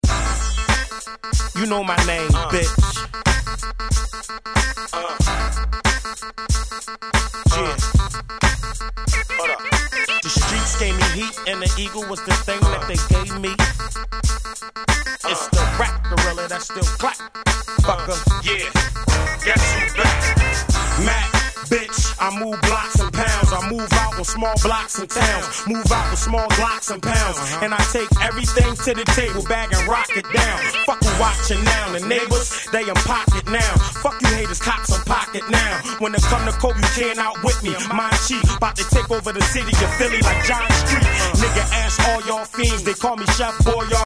01' Big Hit Hip Hop !!